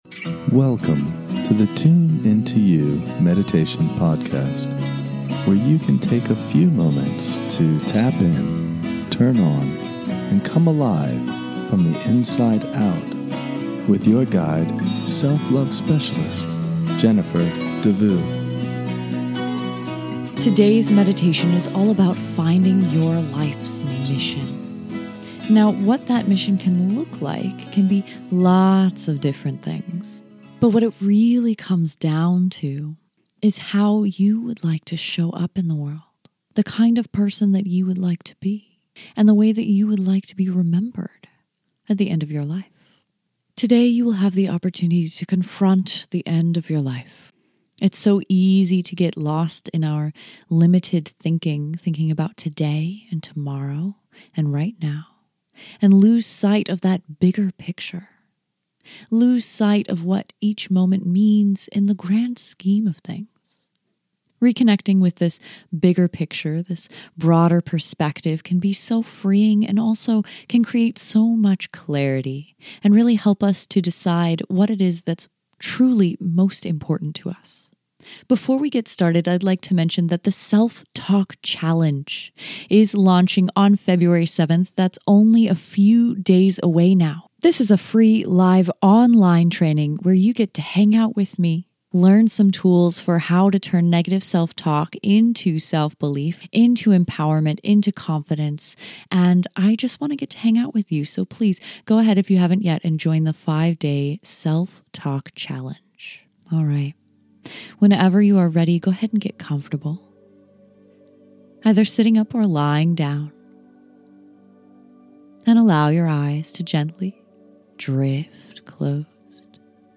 Life Purpose Meditation
In this short guided meditation, we will explore your life's mission and purpose.